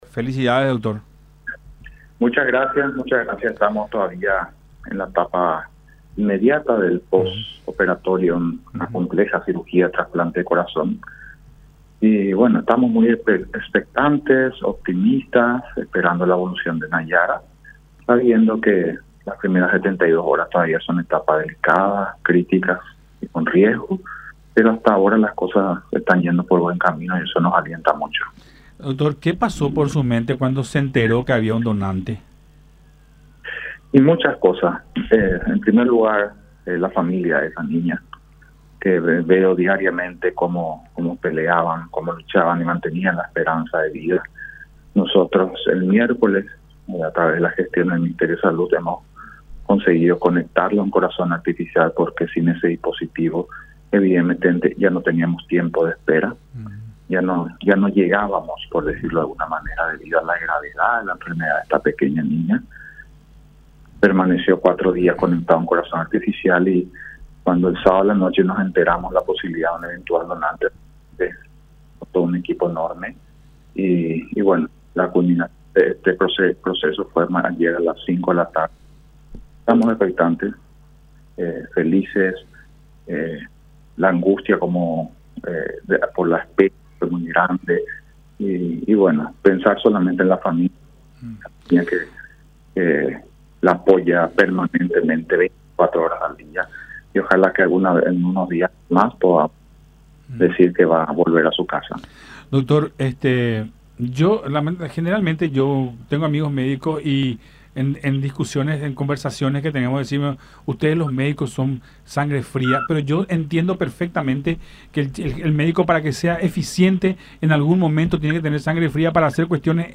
en conversación con Nuestra Mañana por Unión TV, en alusión al procedimiento realizado este domingo.